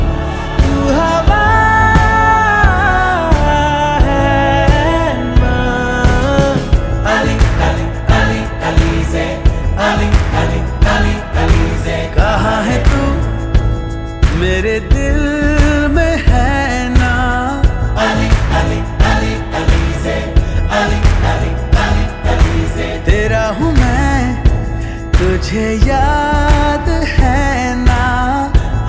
Bollywood Ringtones